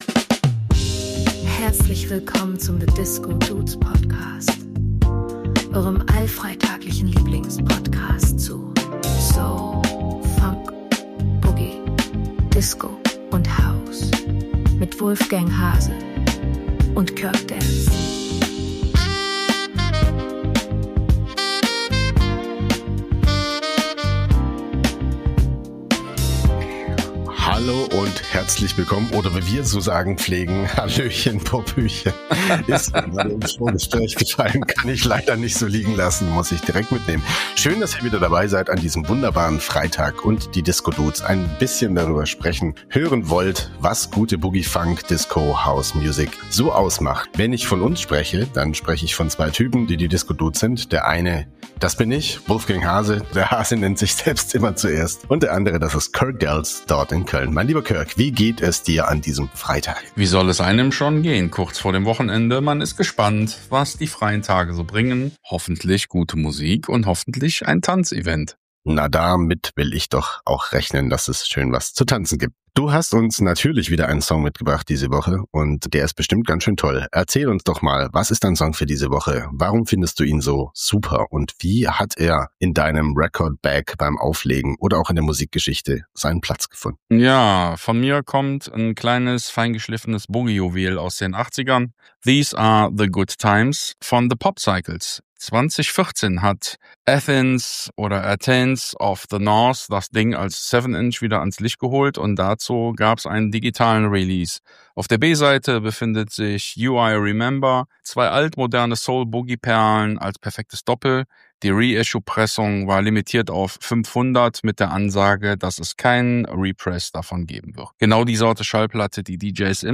🪩 UK Boogie and some funky good times